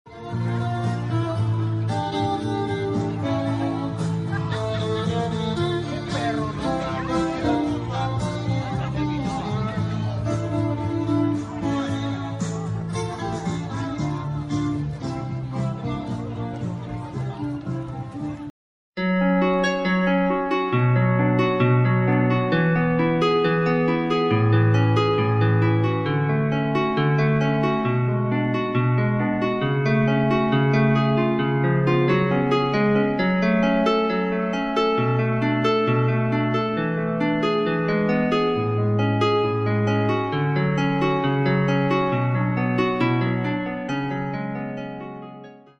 Instrumental version
a classical piece